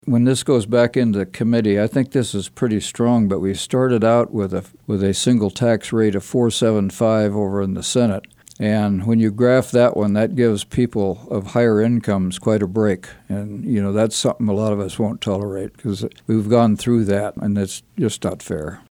Rep. Carlin and Rep. Dodson appeared on KMAN’s In Focus Friday to recap the busy week in the Kansas Legislature.